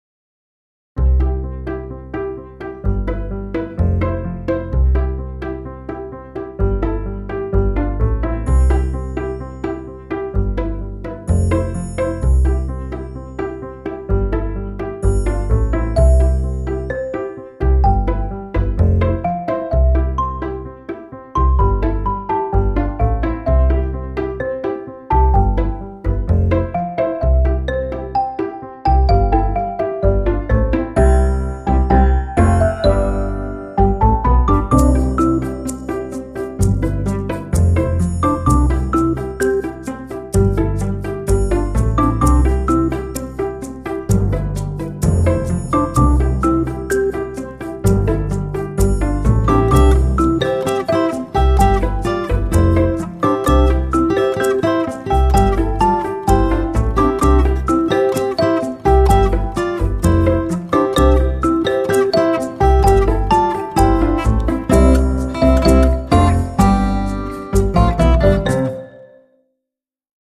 LIGHTEHEARTED